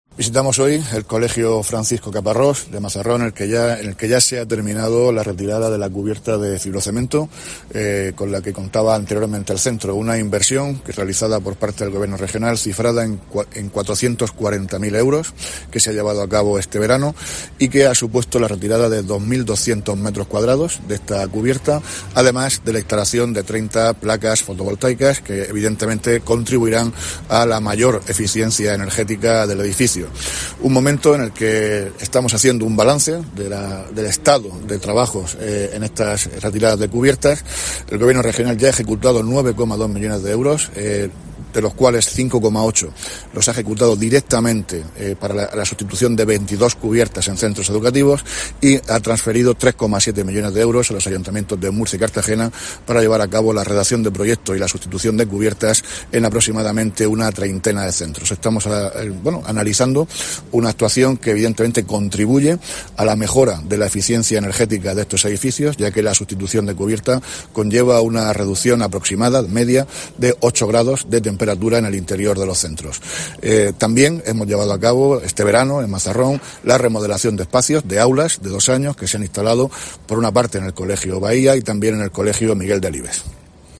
Víctor Marín, consejero de Educación